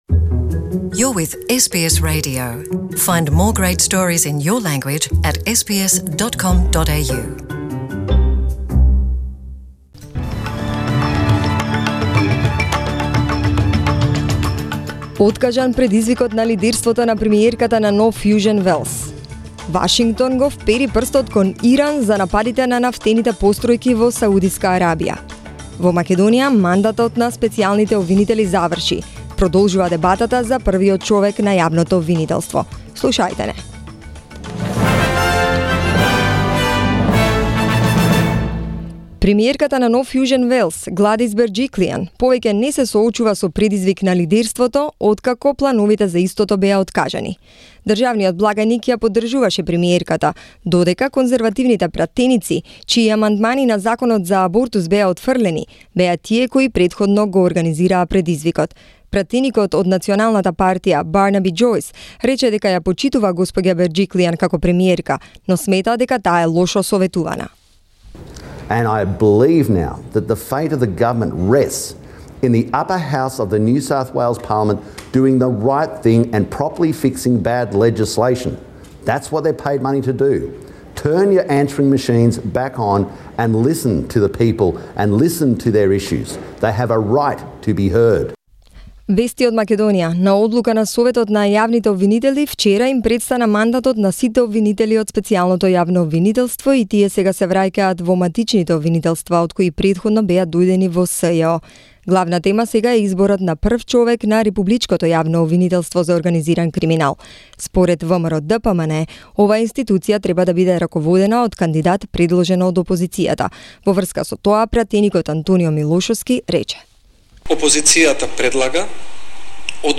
SBS News in Macedonian, 17th September 2019
Listen to SBS Radio news in Macedonian, 17th September